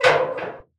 MURDA_PERCUSSION_BRINX.wav